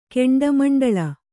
♪ keṇḍa maṇḍaḷa